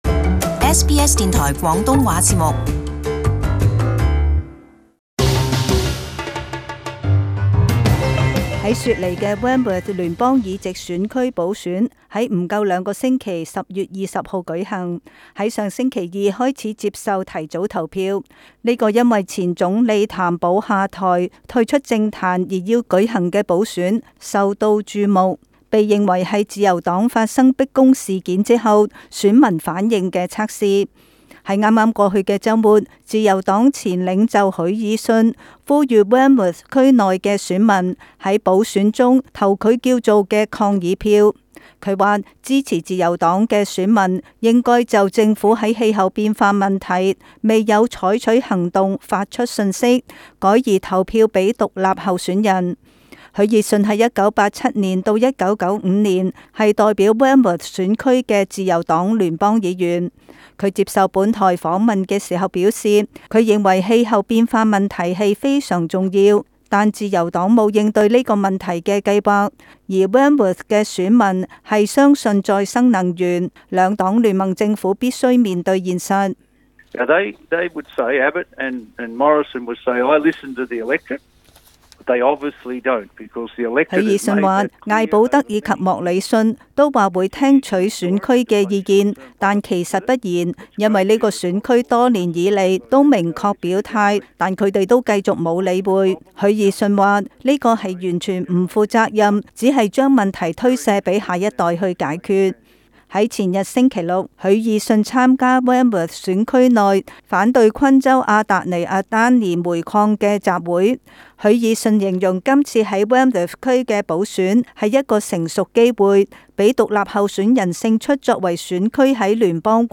【時事報導】許爾遜倒戈籲支持獨立候選人